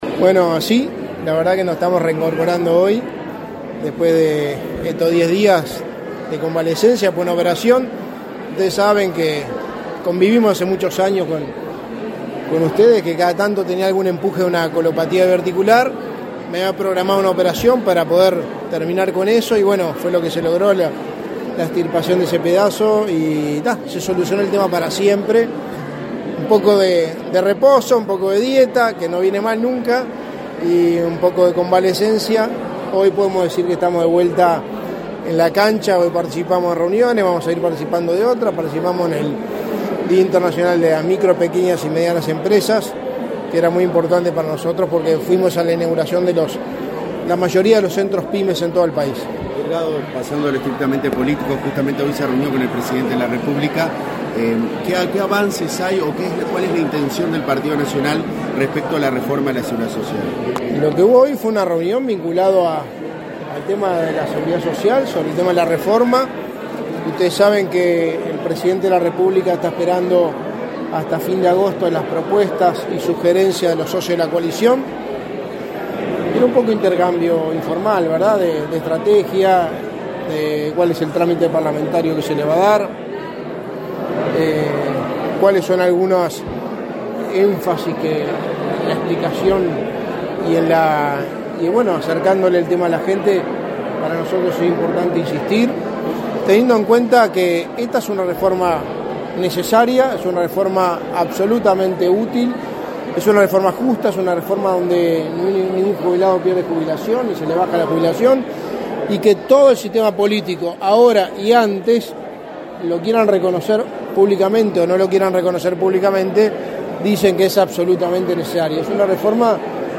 Declaraciones a la prensa del secretario de la Presidencia, Álvaro Delgado
Tras el evento, Delgado efectuó declaraciones a la prensa.